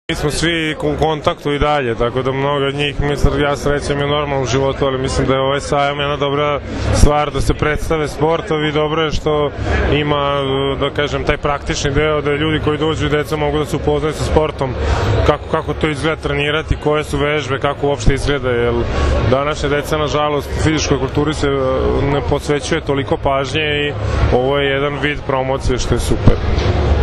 IZJAVA ANDRIJE GERIĆA